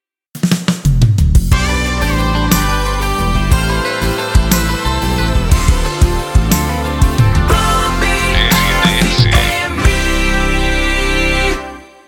Volta de Bloco Curta